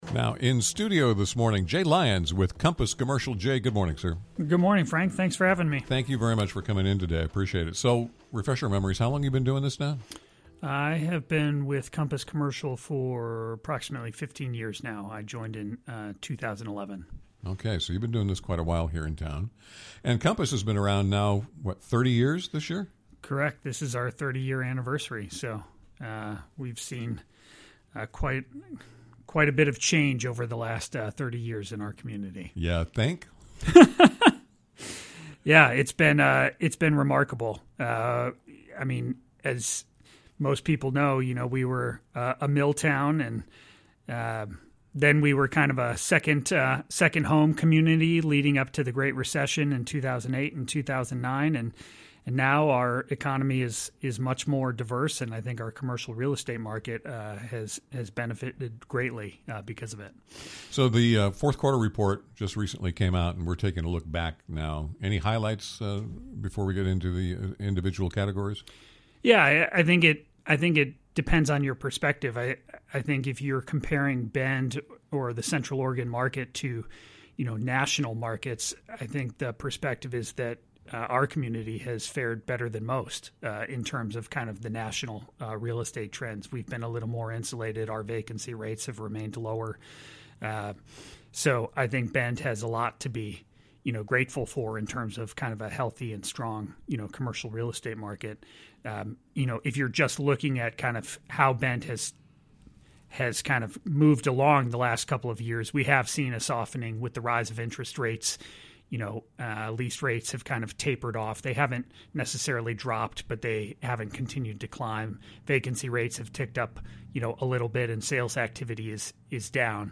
The interview finishes up with advice for buyers, sellers, and tenants in the area.